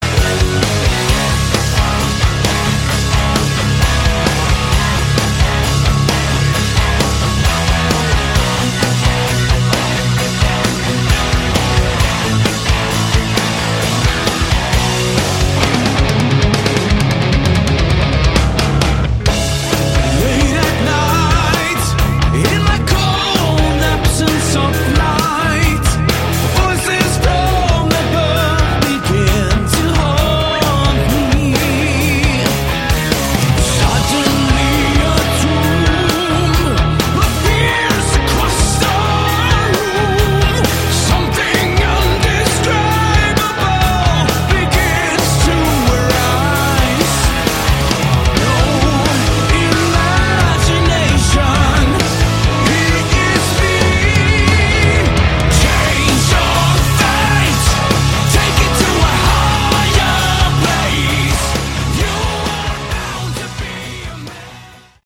Category: Hard Rock
guitar, backing vocals
vocals
keyboards
bass
drums